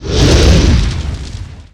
Dragon Breath Fire 4 Sound
horror